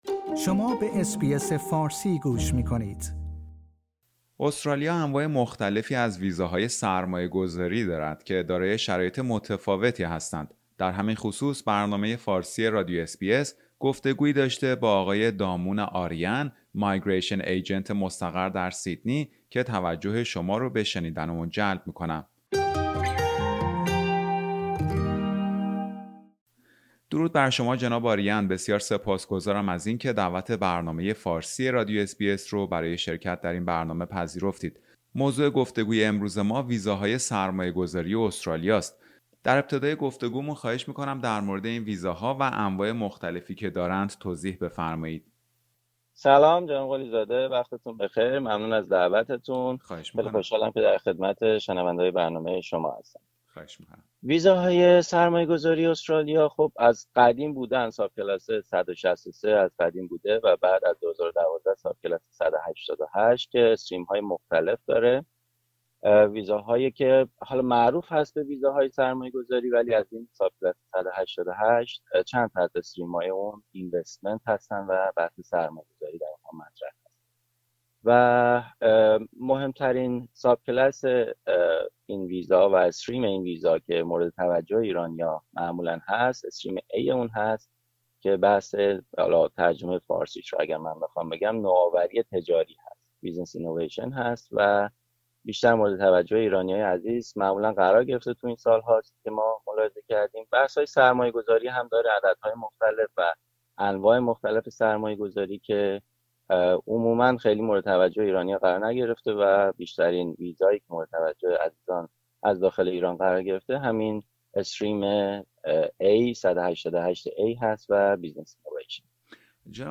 یکی از این ویزاها، ویزای نوآوری تجاری است که مورد توجه بسیاری از ایرانیانی قرار گرفته است که قصد مهاجرت به استرالیا دارند. در همین خصوص برنامه فارسی رادیو اس بی اس گفتگویی داشته